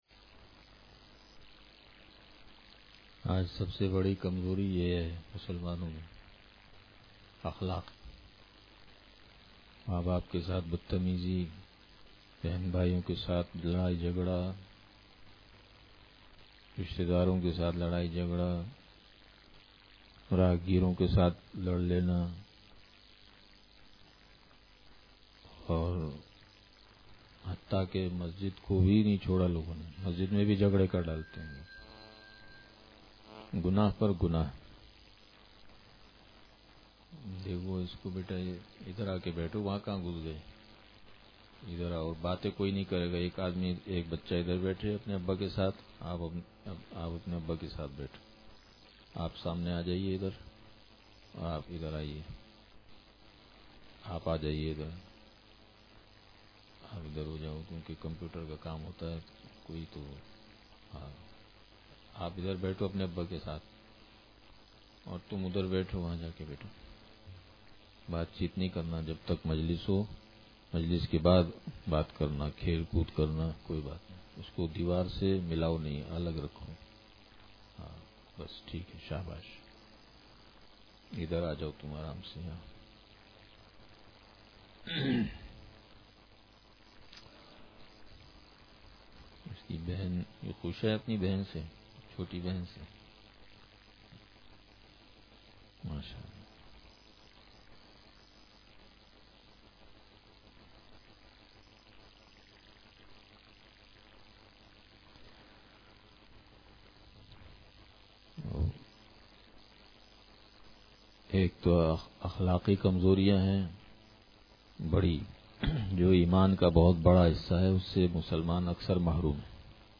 بیان – حسن اخلاق کی اہمیت